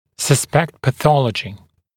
[sə’spekt pə’θɔləʤɪ][сэ’спэкт пэ’солэджи]подозревать наличие патологии